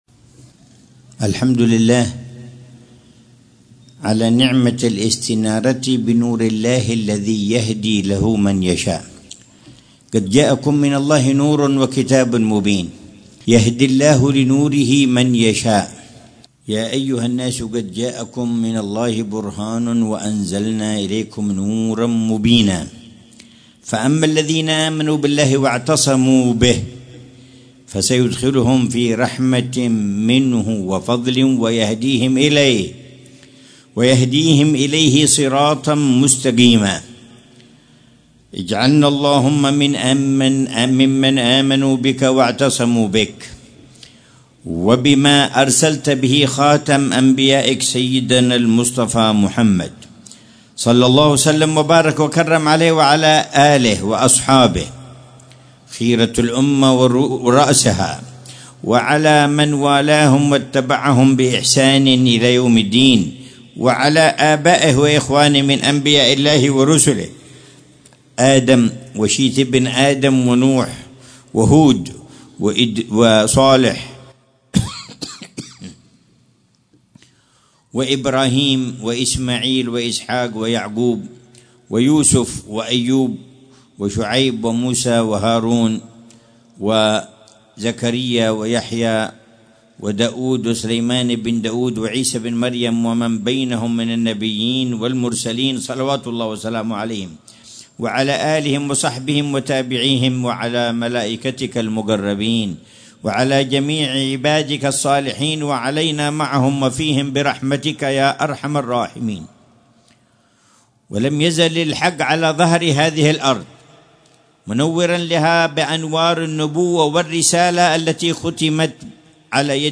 محاضرة العلامة الحبيب عمر بن محمد بن حفيظ في جلسة الجمعة الشهرية الـ56، في الساحة الشرقية لمسجد الشيخ عمر المحضار/ باستضافة حارتي المحيضرة والمحضار، بمدينة تريم، ليلة السبت 18 رجب الأصب 1446هـ، بعن